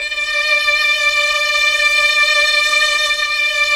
Index of /90_sSampleCDs/Roland LCDP09 Keys of the 60s and 70s 1/KEY_Chamberlin/STR_Cham Slo Str